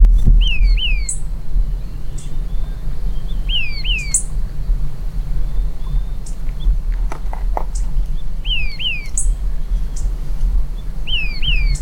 Purple-throated Euphonia (Euphonia chlorotica)
Sex: Both
Condition: Wild
Certainty: Observed, Recorded vocal